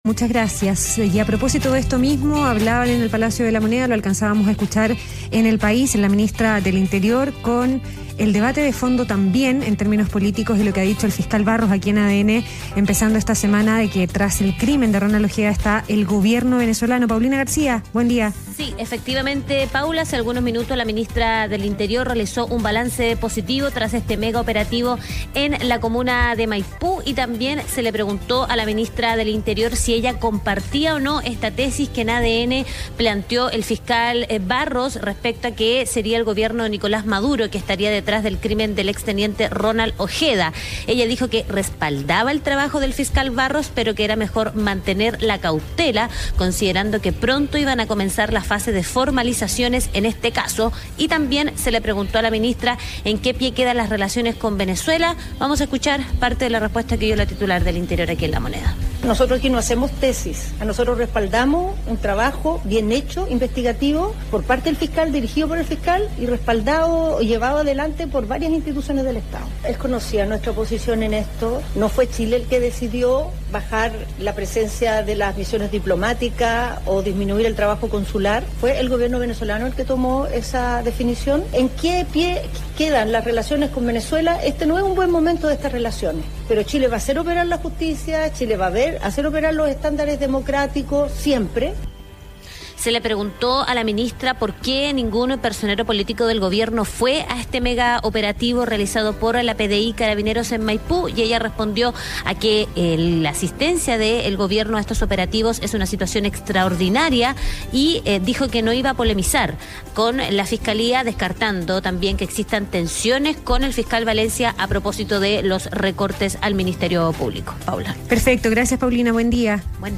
Bajo ese contexto, en un punto de prensa desde La Moneda, la ministra Tohá expresó su respaldo al trabajo del fiscal Barros, pero llamó a la cautela ante la proximidad de las formalizaciones en este caso.